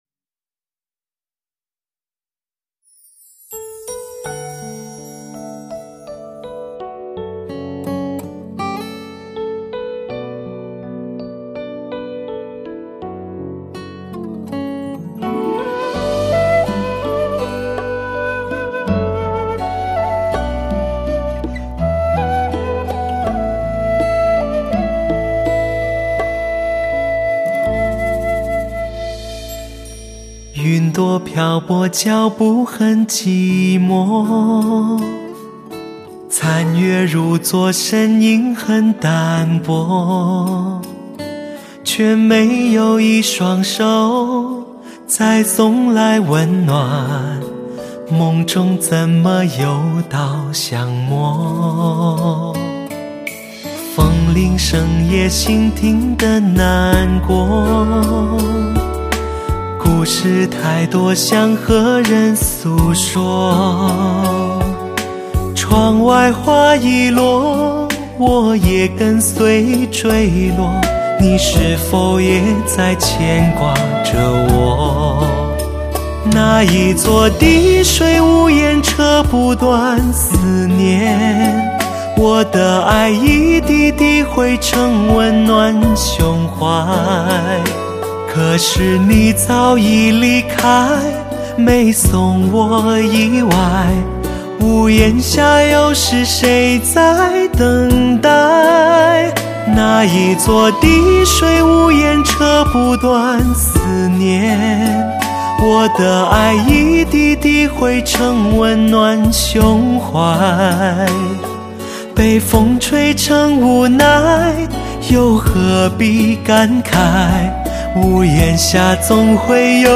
音乐类型: 天籁人声/POP